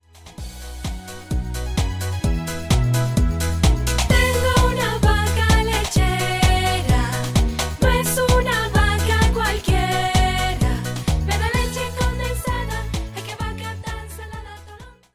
Vaca-lechera.mp3